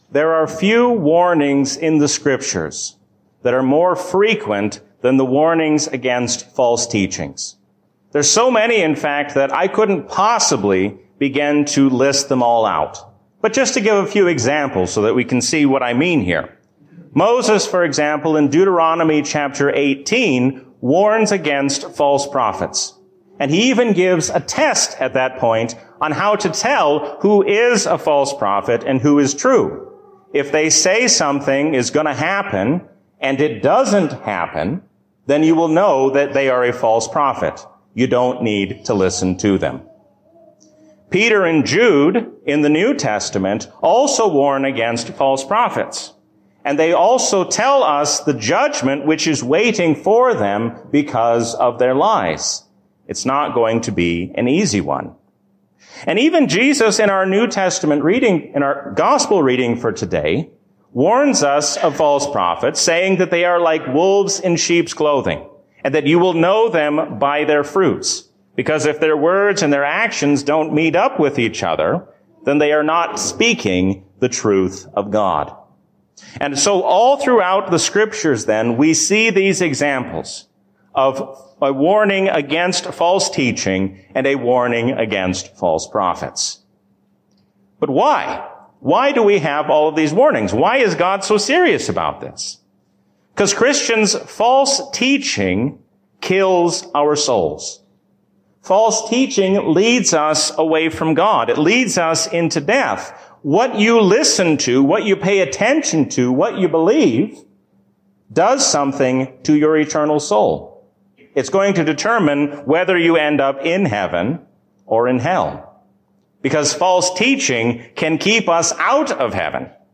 A sermon from the season "Trinity 2022." Stand up and speak up for the truth of God's Word, because the truth is worth fighting for.